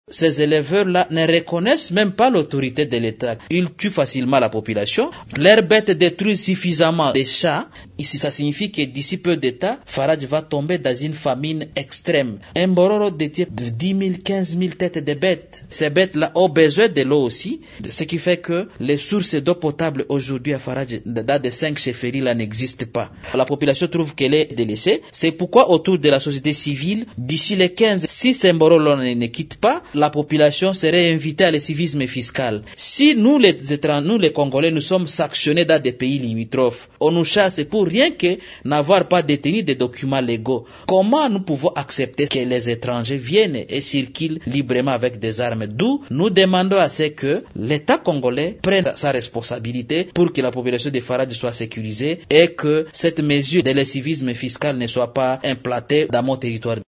Le député national élu de Faradje, Jean-Pierre Anokonzi Masikini demande au gouvernement de s’impliquer pour renvoyer les éleveurs Mbororo dans leurs pays d’origine. Il a lancé cet appel lors d’une déclaration politique à la presse samedi 8 juin à Kinshasa.